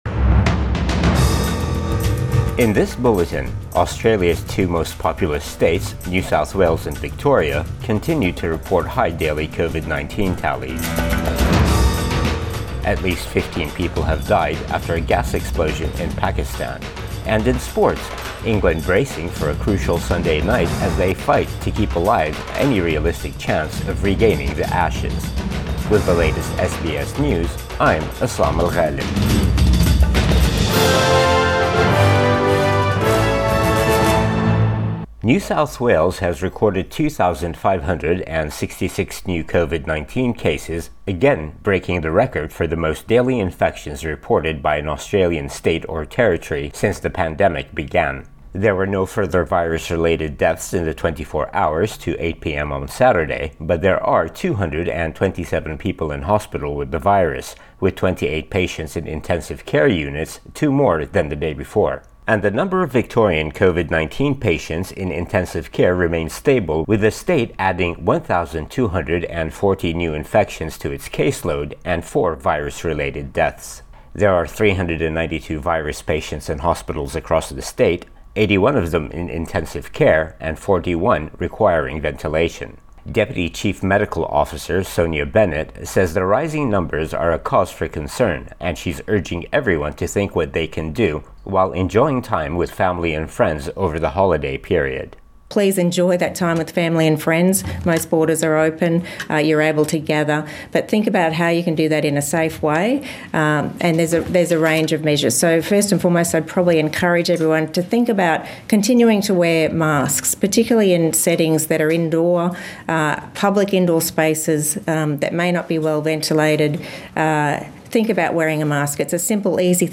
Midday bulletin 19 December 2021